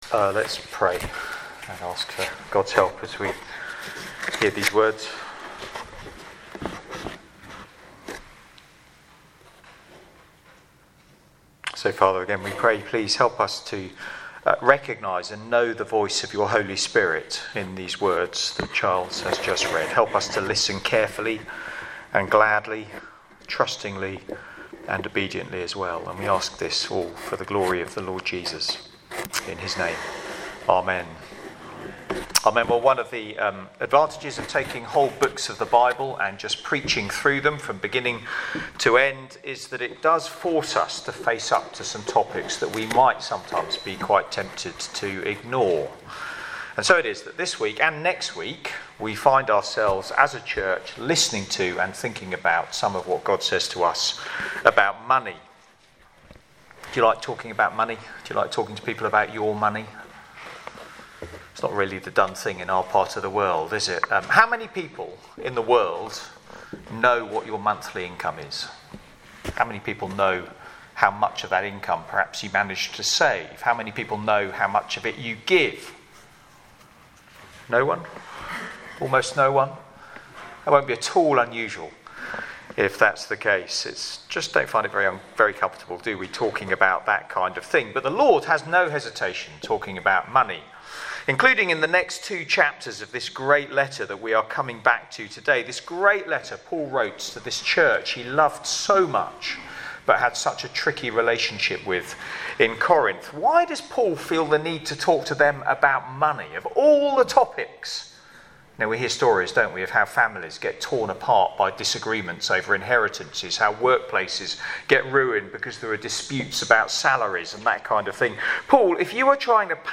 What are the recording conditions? Media for Sunday Evening on Sun 21st Jan 2024 18:00